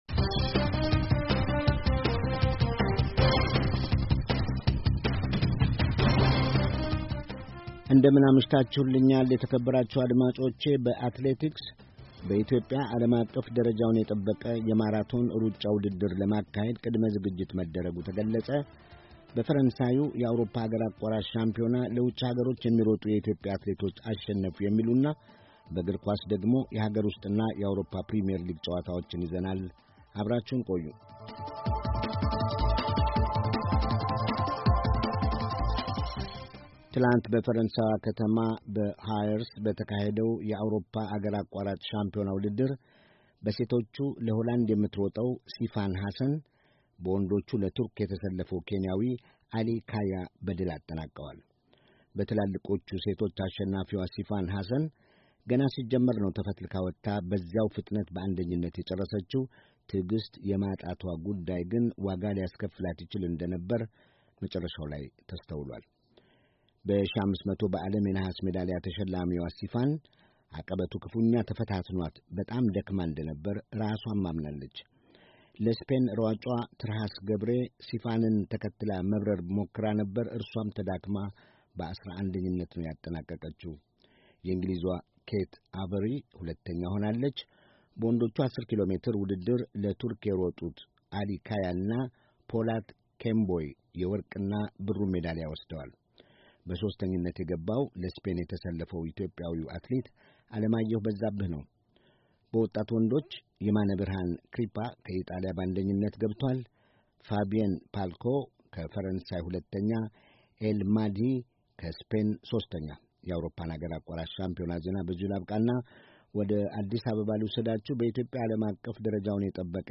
ሳምንታዊ የስፖርት ዜና